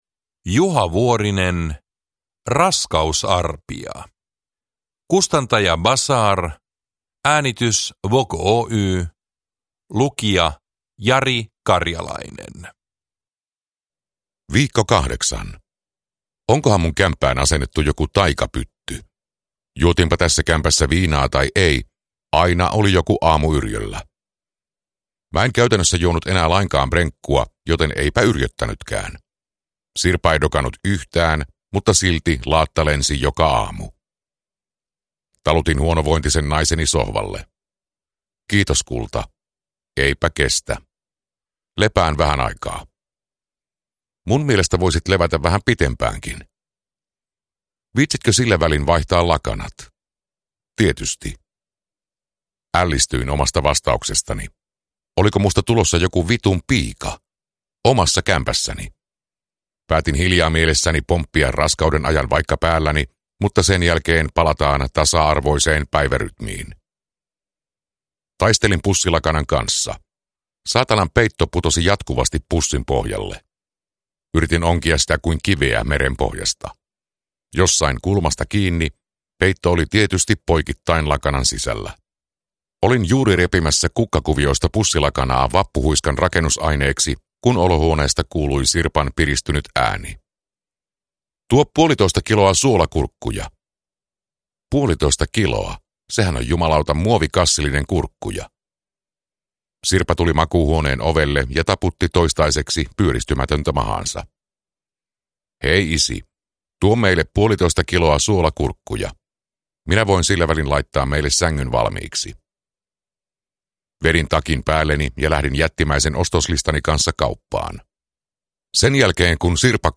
Raskausarpia – Ljudbok